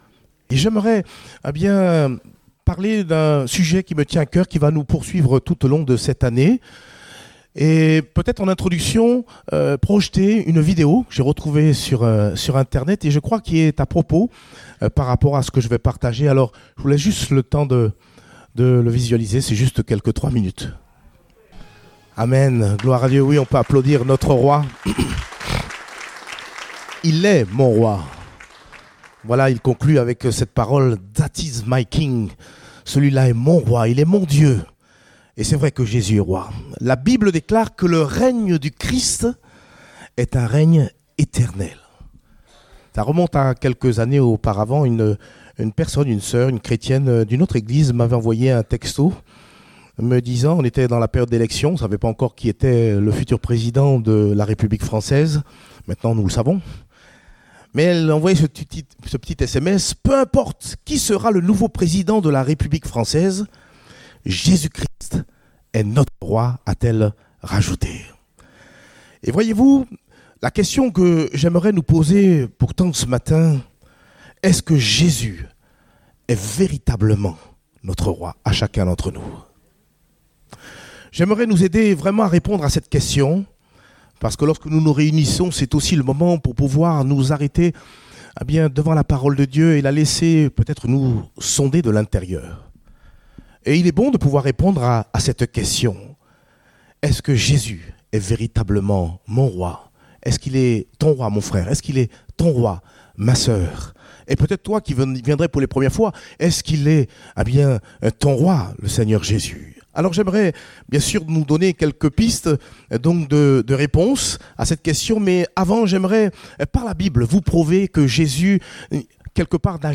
Date : 5 janvier 2020 (Culte Dominical)